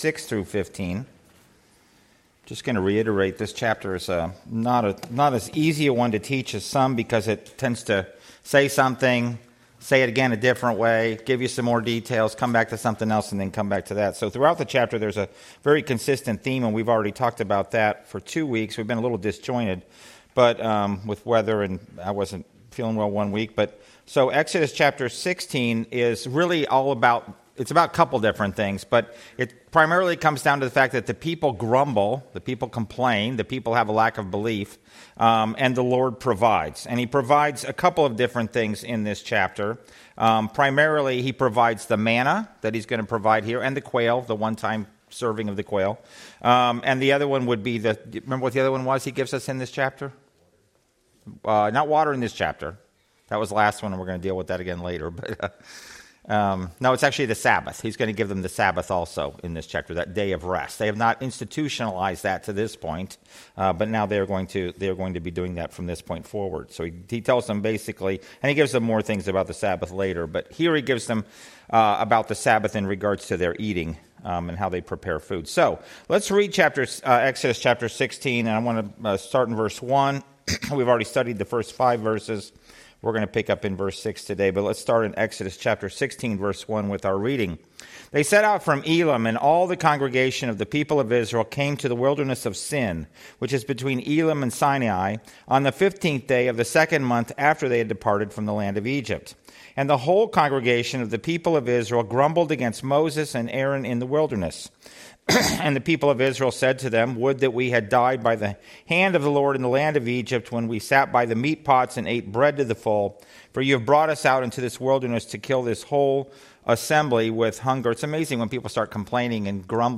Exodus 16 Service Type: Sunday School « Godly Living The Glorious Return of Christ in the Old Testament